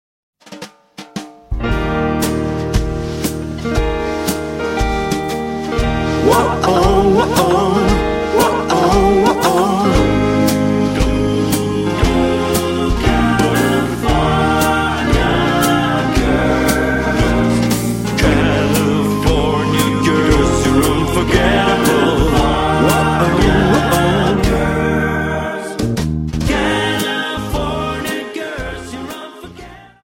Dance: Slowfox
Slowfox 29